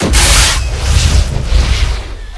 attack_stop1.ogg